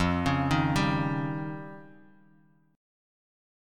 F7sus2#5 chord